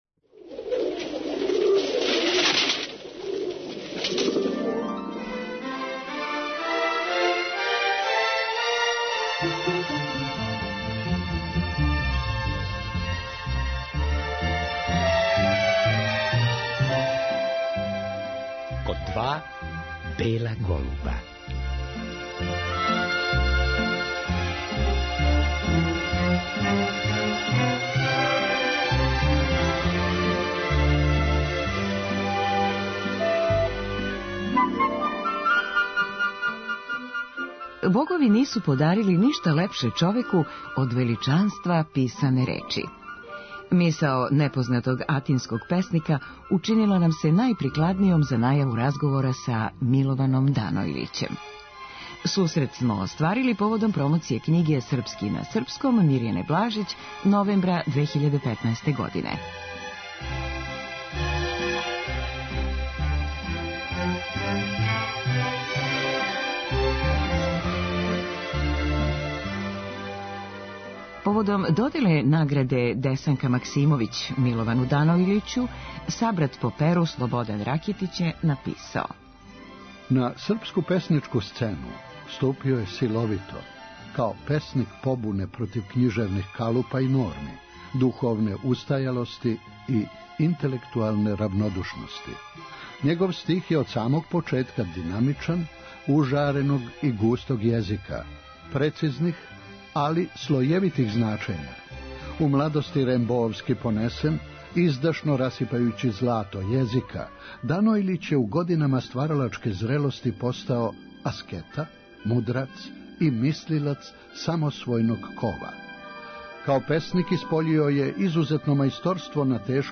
Разговор је снимљен новембра 2015. године.